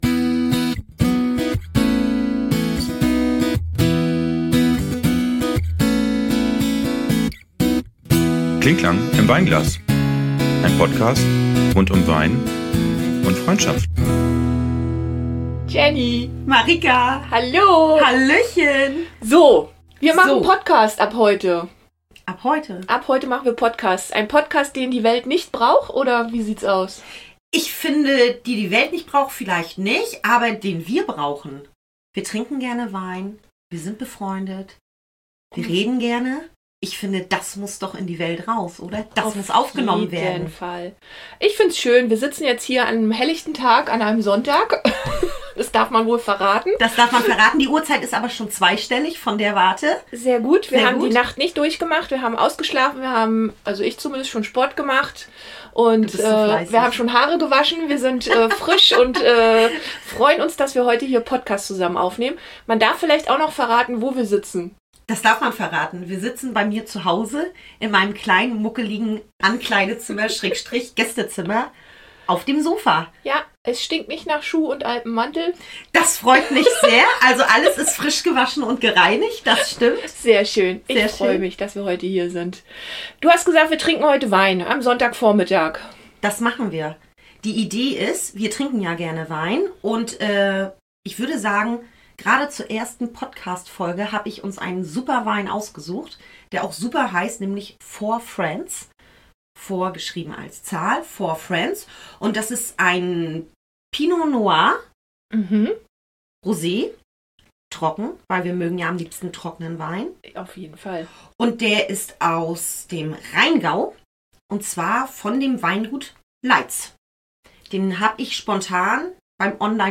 Und das jeden Monat mit einem neuen Wein im Live-Tasting. In der ersten Folge wird schon gesungen und versucht sich in nur 1Minute tiefgründig vorzustellen ....